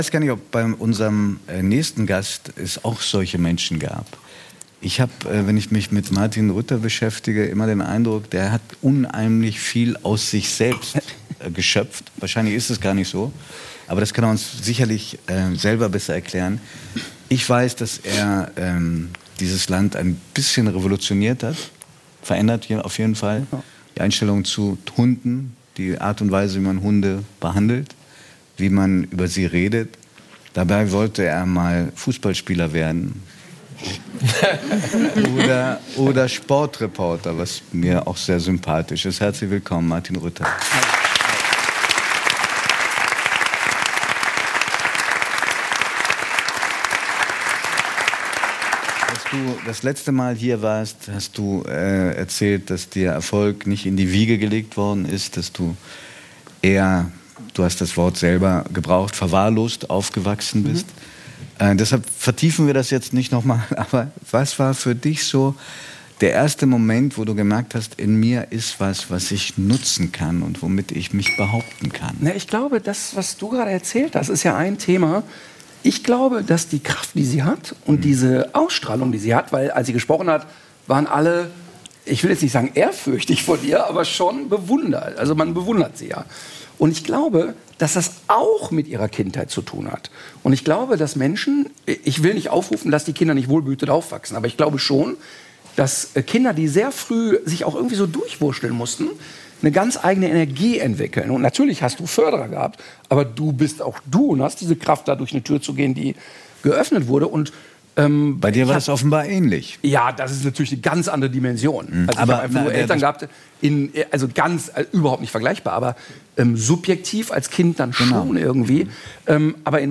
Martin Rütter – Hundetrainer ~ 3nach9 – Der Talk mit Judith Rakers und Giovanni di Lorenzo Podcast